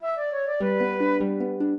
flute-harp
minuet12-11.wav